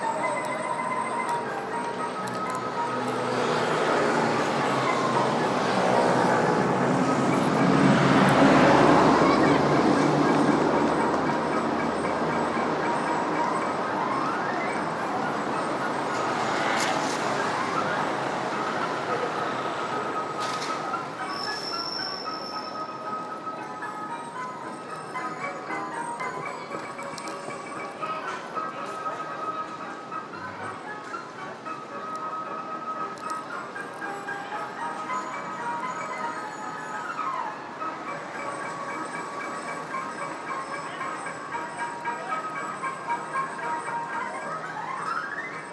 Organillo en plena Barcelona
Aunque típico de Madrid, hemos podido captar uno de pequeñas dimensiones en pleno barrio de Sants. El sonido está muy lleno de ruido de la calle